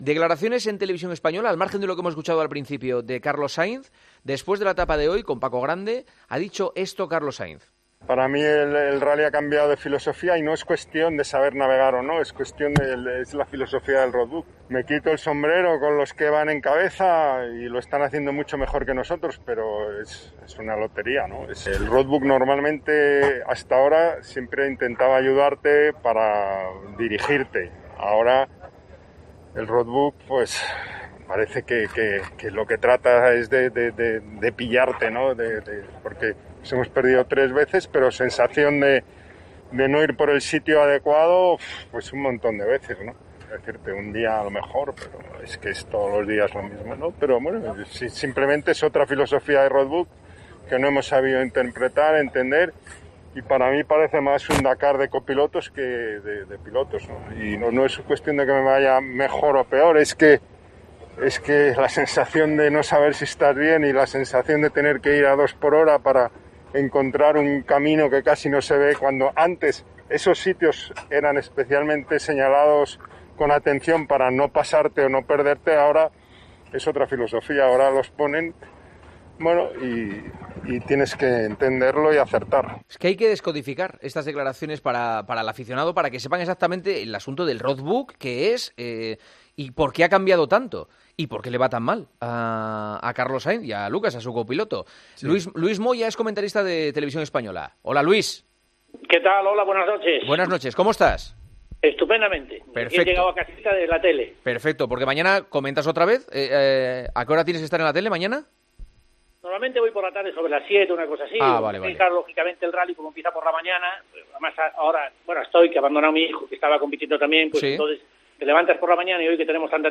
AUDIO - ESCUCHA LA ENTREVISTA A LUIS MOYA, EN EL PARTIDAZO DE COPE